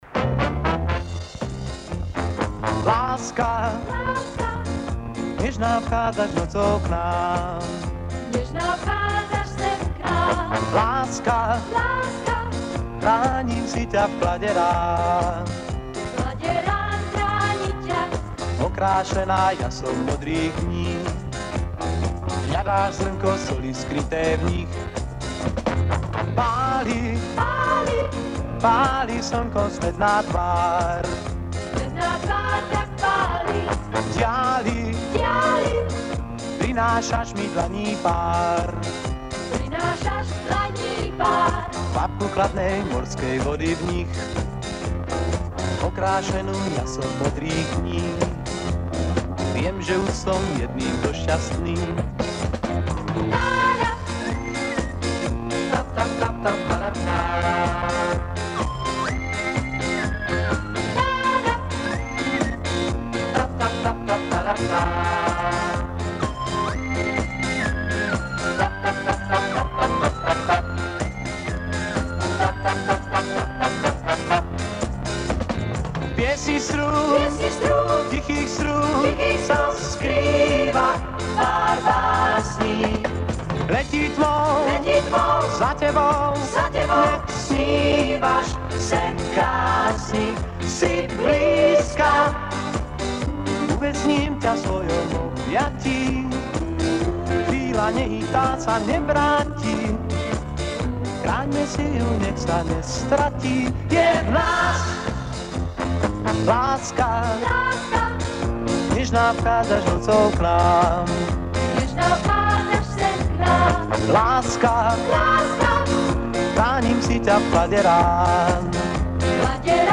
Песня словацкая, 1976 года.